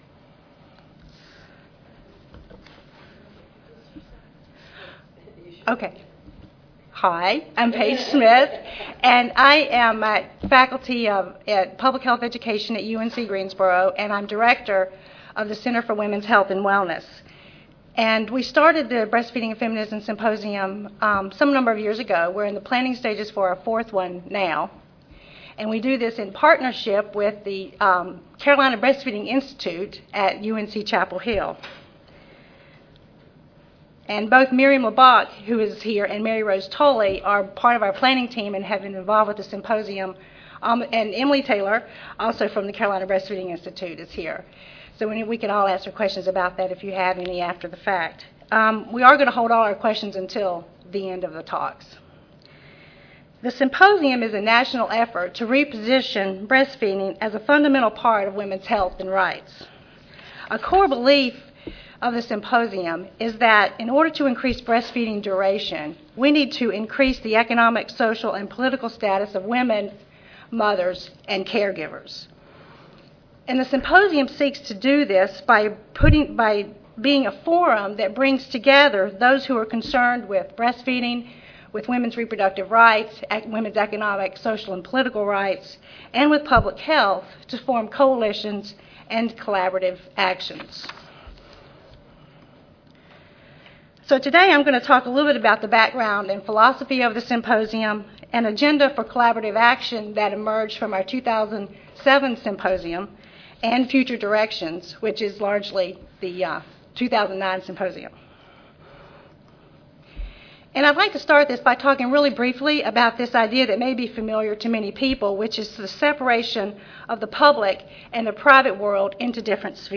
Oral